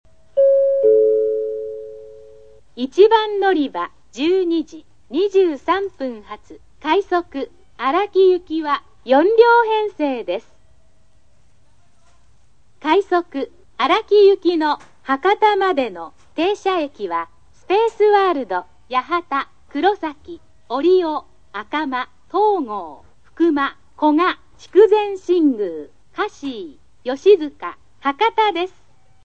スピーカー：ソノコラム
案内放送（快速・荒木）　(142KB/29秒)